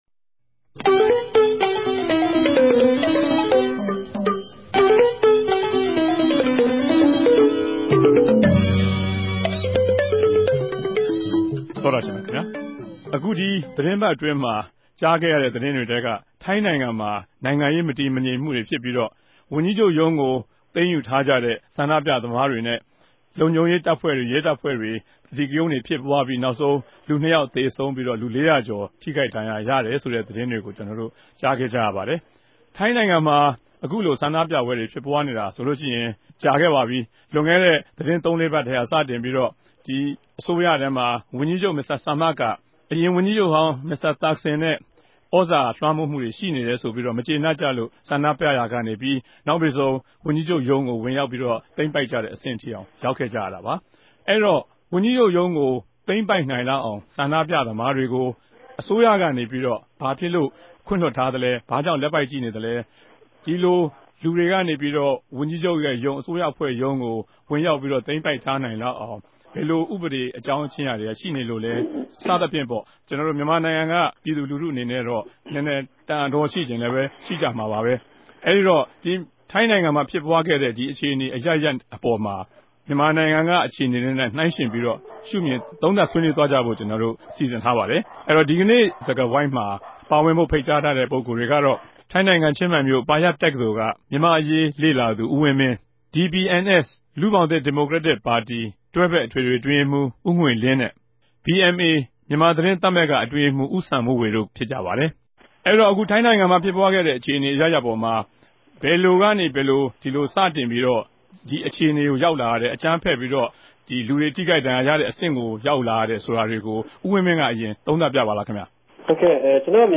္ဘပီးခဲ့တဲ့ရက်ပိုင်းတေကြ ထိုင်းိံိုင်ငံ၊ ဘန်ကောက်္ဘမိြႚမြာ ပဋိက္ခတြေ ူဖစ်ပြားခဲ့ုကရာမြာ အုကမ်းဖက်တဲ့အတြက် ရဲတပ်ဖြဲႚကို ဒေၝသထြက်နေုကသူတြေ ရြိသလို၊ ူပည်သူတေဖြက်က ရပ်တည်ပၝတယ်ဆိုတဲ့ စစ်တပ်ကိုလည်း လေးစားမြတြေ တိုးပြားလာပၝတယ်။ အခုတပတ် စကားဝိုင်းမြာ ဒီအေုကာင်းတေကြို ဆြေးေိံြးတင်ူပထားပၝတယ်။